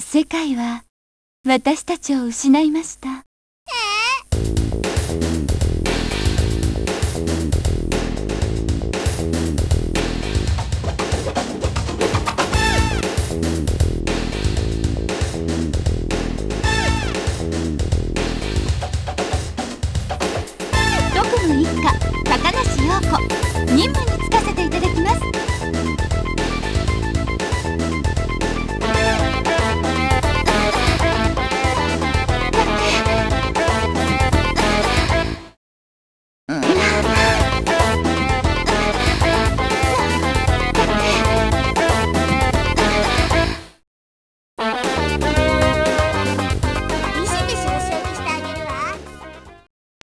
ArrangeMusic
有名声優熱演のボイスとBGMを完全リミックス、
完全アレンジバージョンとして生まれ変わったサイバーミュージックを、